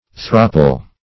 Search Result for " thrapple" : The Collaborative International Dictionary of English v.0.48: Thrapple \Thrap"ple\, n. [Also thropple, corrupted fr. throttle.] Windpipe; throttle.
thrapple.mp3